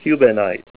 Help on Name Pronunciation: Name Pronunciation: Cubanite
Say CUBANITE Help on Synonym: Synonym: Chalmersite   ICSD 67529   PDF 47-1749
CUBANITE.WAV